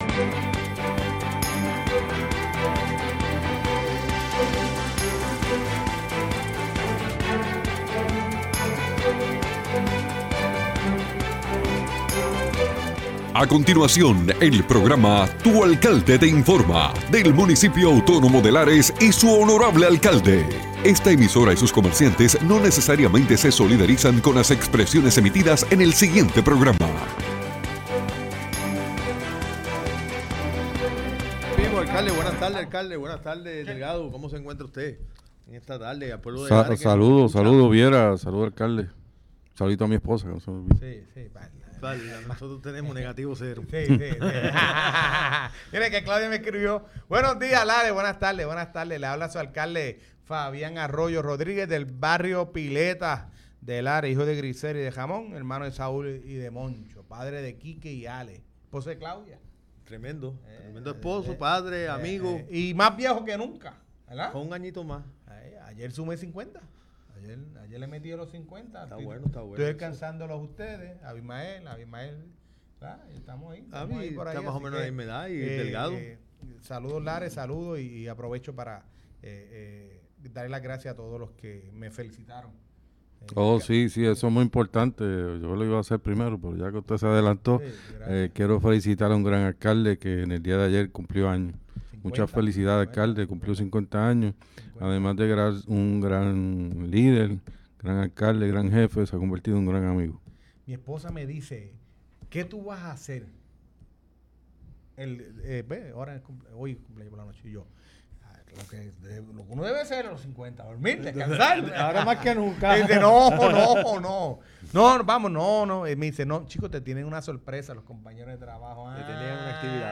El honorable alcalde de Lares, Fabián Arroyo, junto a su equipo de trabajo nos informan sobre todo las novedades del municipio.